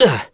Esker-Vox_Damage_01.wav